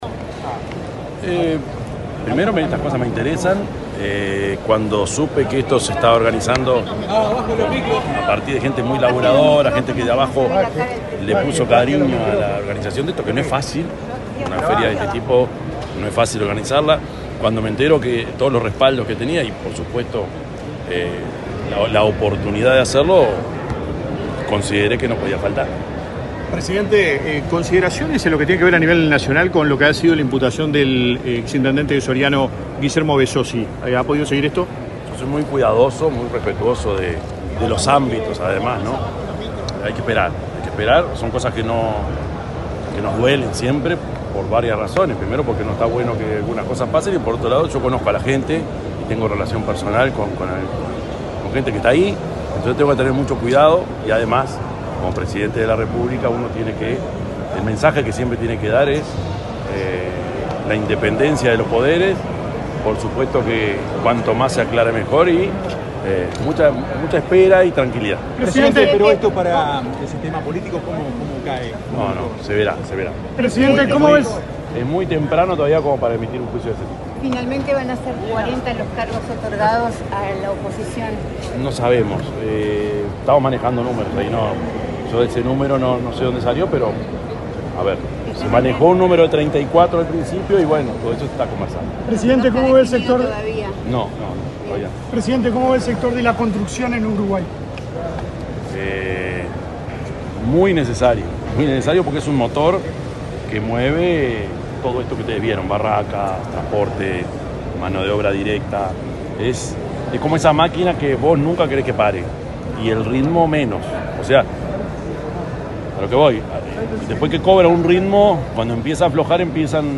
Declaraciones a la prensa del presidente Yamandú Orsi
El presidente de la República, profesor Yamandú Orsi, dialogó con la prensa, luego de participar en la apertura de la Expo Construye 2025, que se